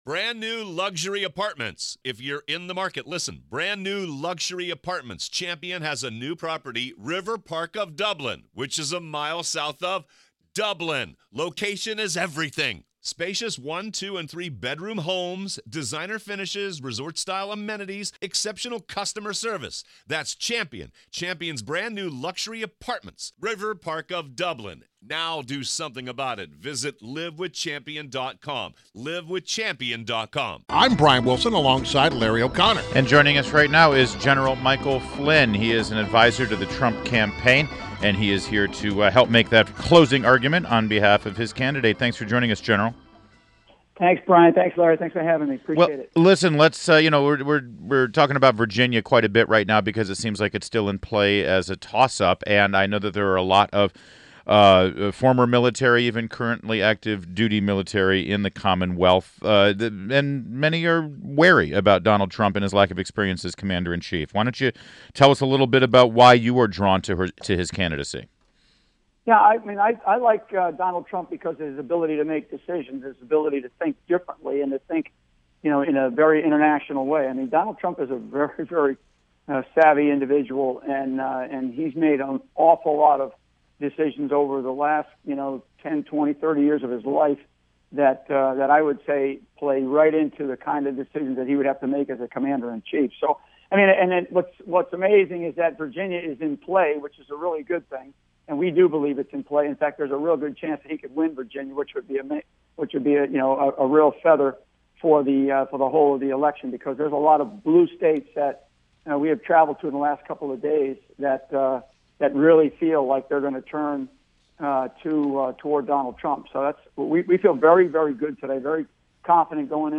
WMAL Interview - GEN. MIKE FLYNN - 11.08.16